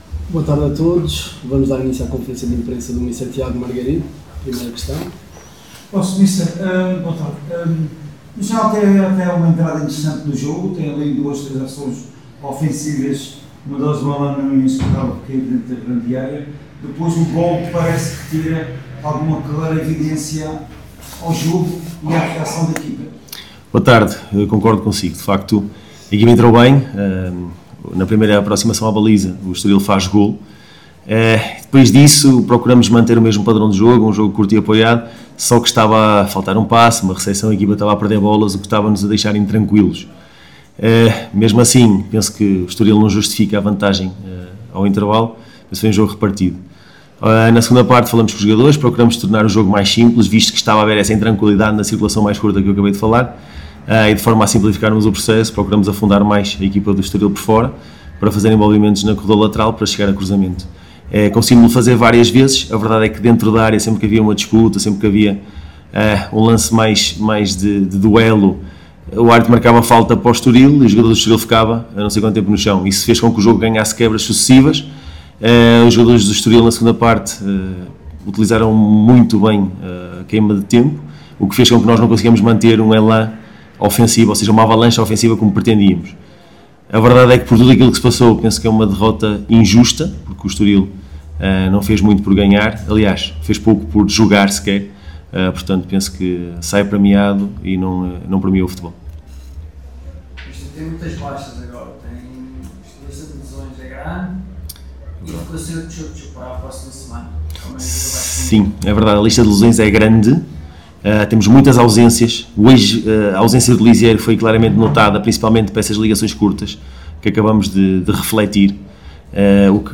Na conferência de imprensa realizada no final do encontro para a 26.ª jornada da Liga Portugal Betclic